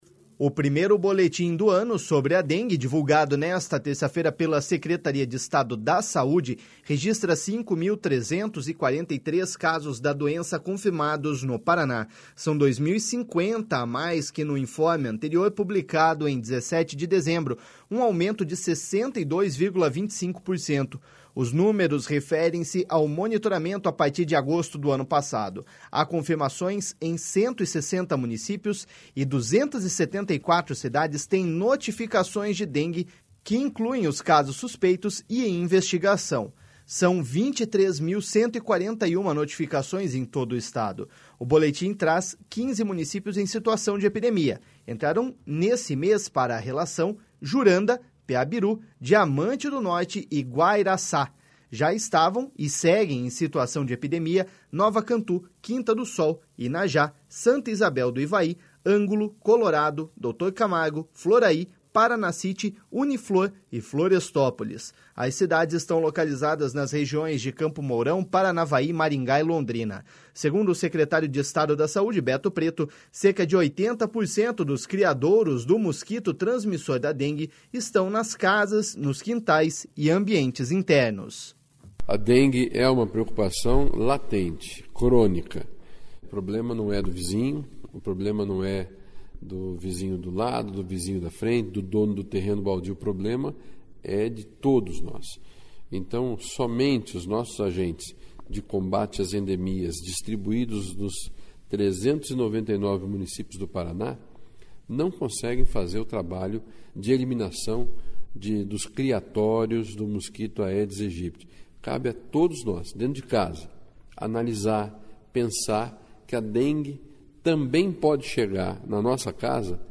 Segundo o secretário de Estado da Saúde, Beto Preto, cerca de 80% dos criadouros do mosquito transmissor da dengue estão nas casas, nos quintais e ambientes internos.// SONORA BETO PRETO.//